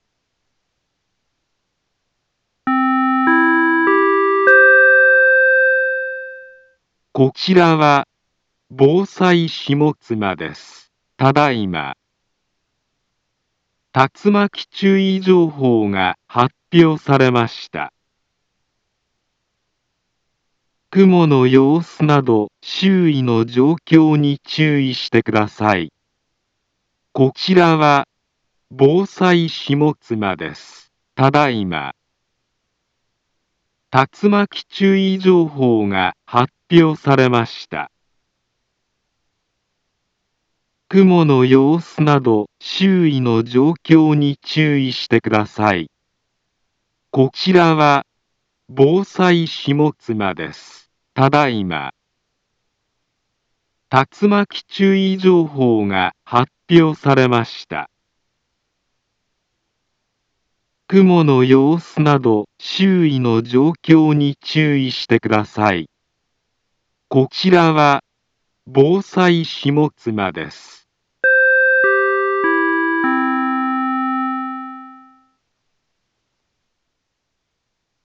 Back Home Ｊアラート情報 音声放送 再生 災害情報 カテゴリ：J-ALERT 登録日時：2024-08-19 20:44:30 インフォメーション：茨城県北部、南部は、竜巻などの激しい突風が発生しやすい気象状況になっています。